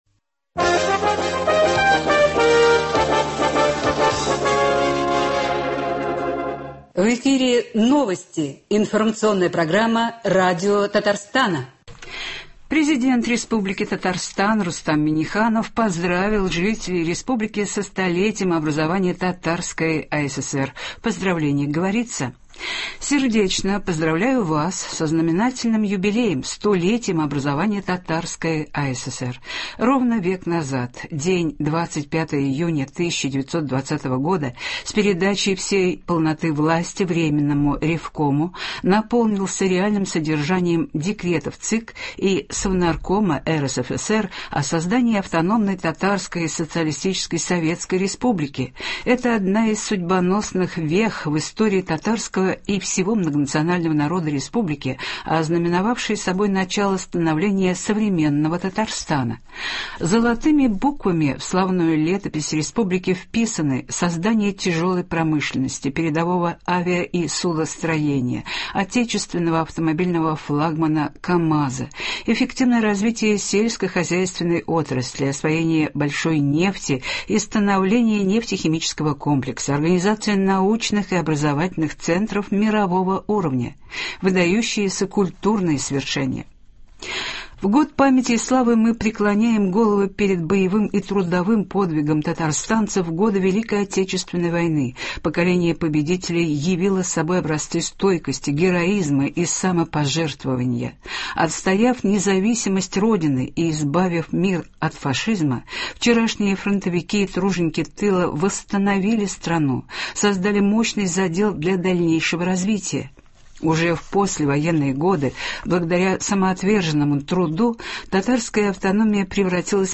Утренний выпуск.